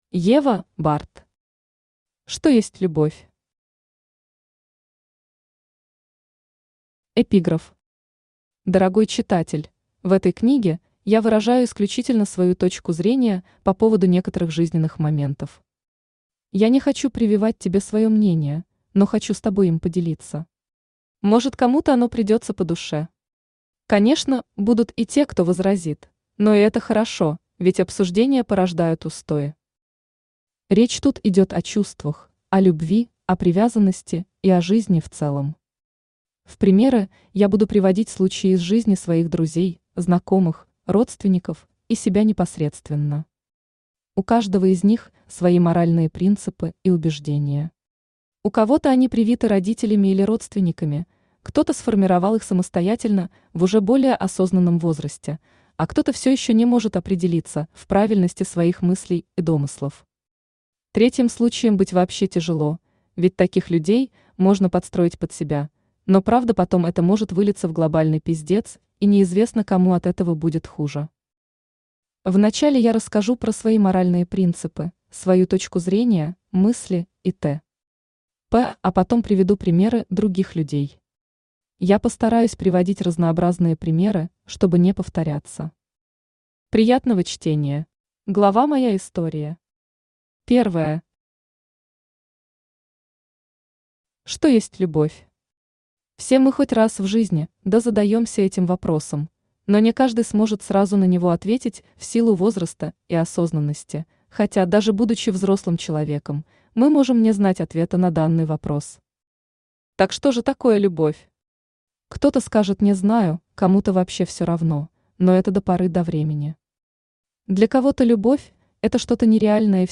Аудиокнига Что есть любовь?
Автор Ева Bardd Читает аудиокнигу Авточтец ЛитРес.